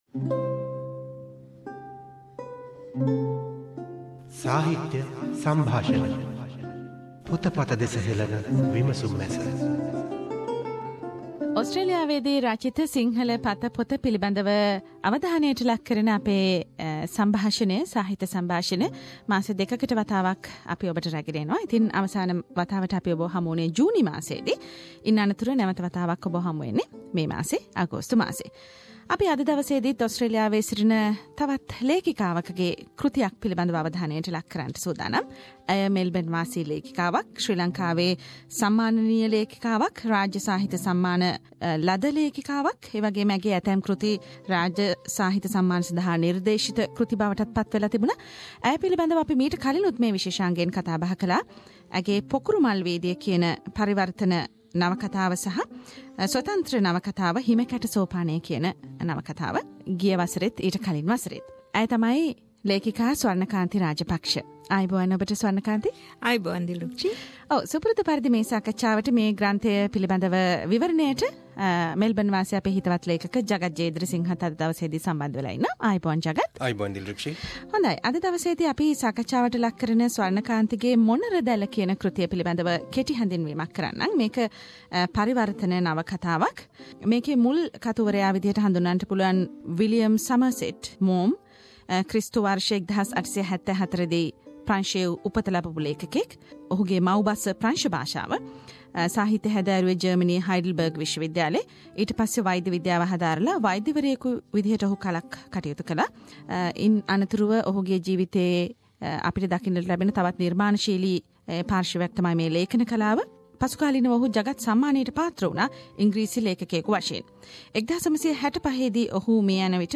SBS Sinhalese Book Review of the Month- The forum of introducing creative Sri Lankan writers in Australia.